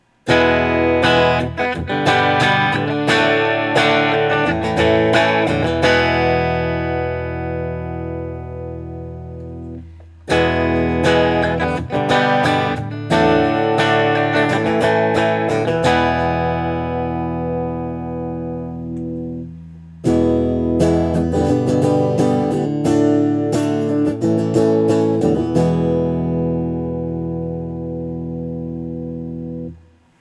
Open Chords #1
For these recordings I used my normal Axe-FX Ultra setup through the QSC K12 speaker recorded into my trusty Olympus LS-10.
For this guitar I recorded each selection with the tone set to 10, then 7, then 0.